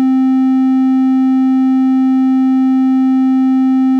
Here’s how a band limited triangle wave looks and sounds compared to a non band limited triangle wave, like the ones we created in the last chapter.
triangle.wav